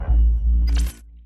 SFX科技感十足的转场音效下载
SFX音效